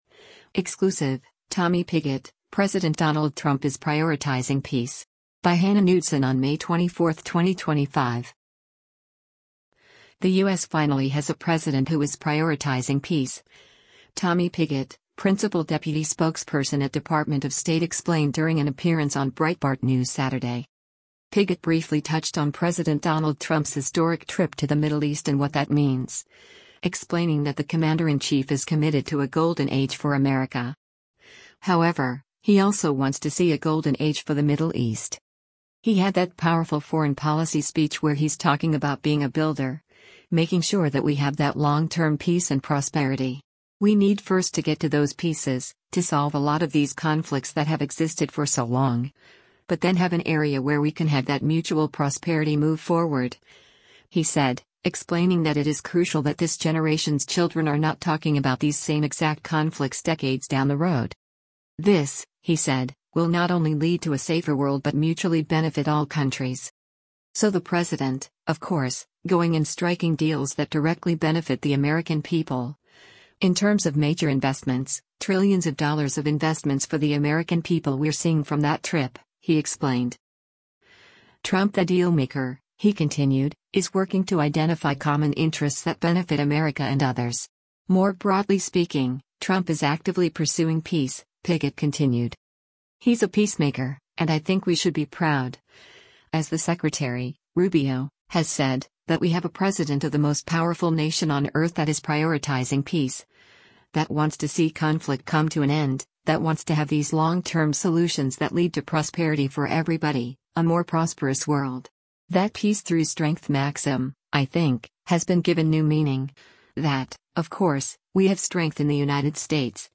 The U.S. finally has a president who is “prioritizing peace,” Tommy Pigott, Principal Deputy Spokesperson at Department of State explained during an appearance on Breitbart News Saturday.
Breitbart News Saturday airs on SiriusXM Patriot 125 from 10:00 a.m. to 1:00 p.m. Eastern.